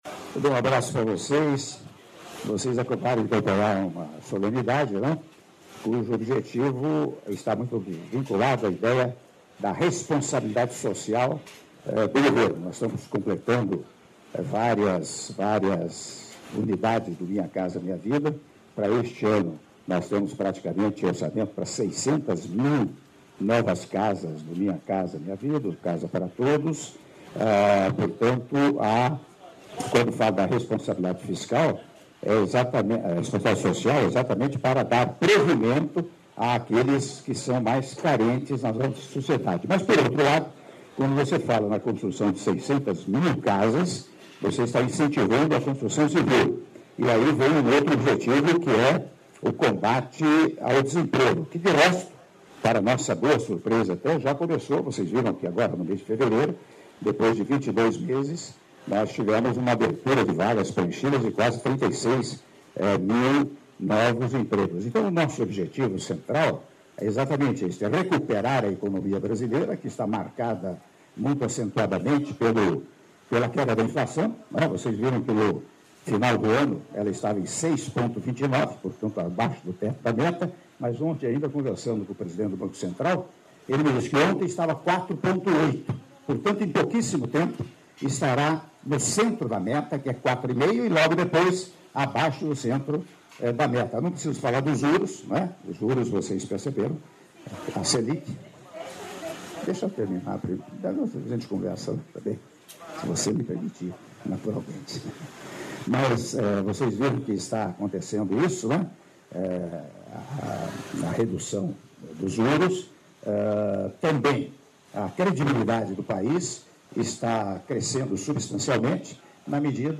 Áudio da entrevista coletiva concedida pelo presidente da República, Michel Temer, após cerimônia de entrega de 1.300 unidades habitacionais do Parque Residencial da Solidariedade do Programa Minha Casa Minha Vida - São José do Rio Preto/SP - (03min28s)